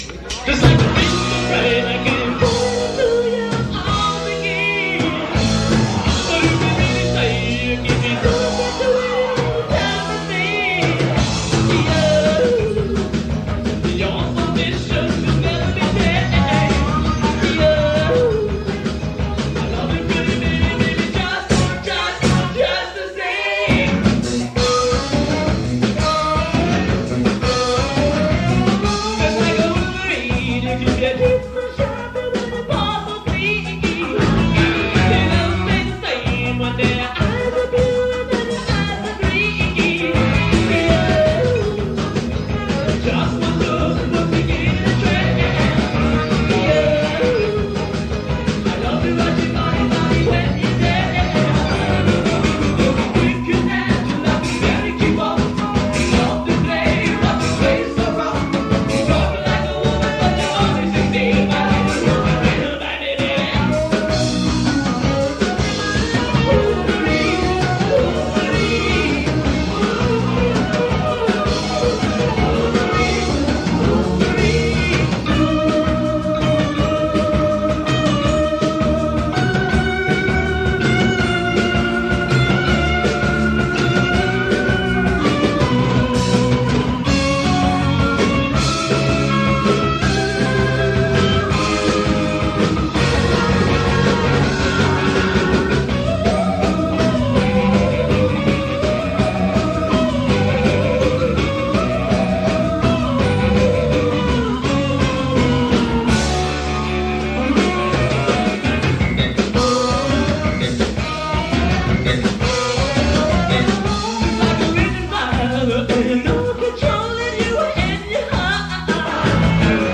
played live
so the only version of it is a live version.